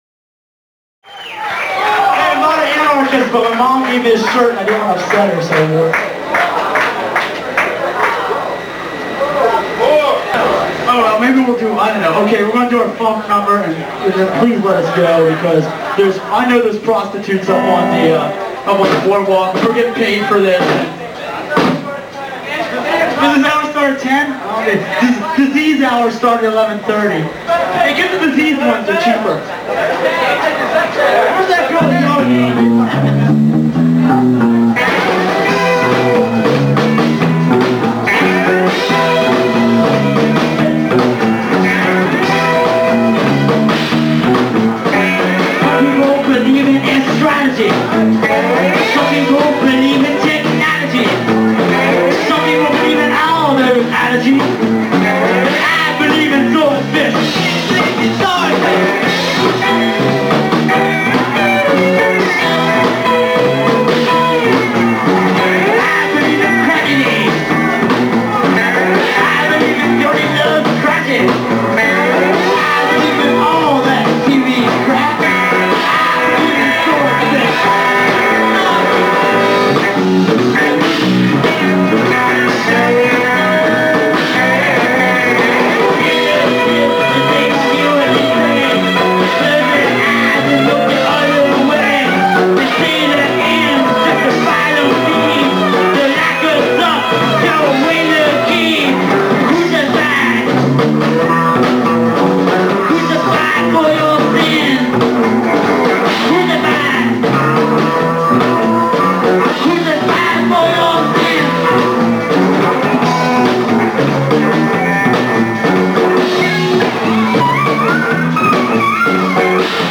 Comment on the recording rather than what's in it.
Elk’s Lodge Atlantic City 1985?